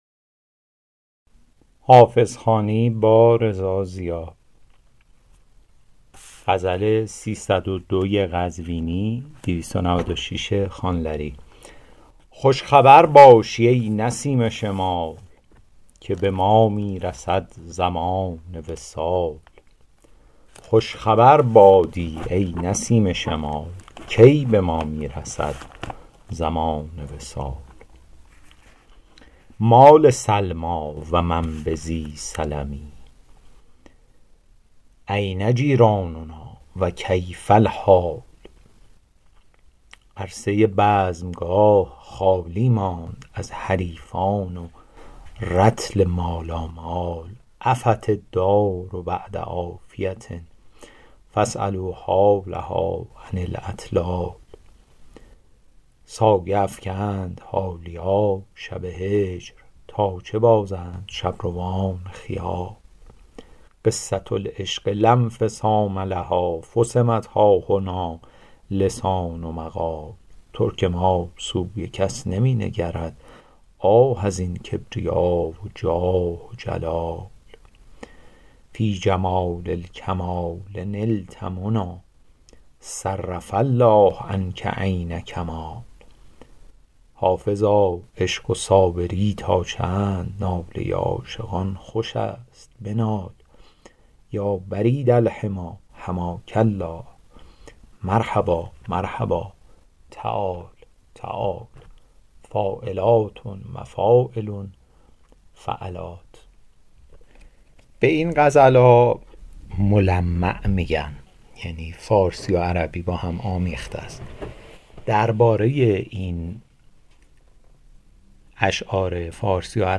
حافظ غزلیات شرح صوتی غزل شمارهٔ ۳۰۲